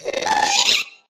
Sound / Minecraft / mob / ghast / charge.ogg
charge.ogg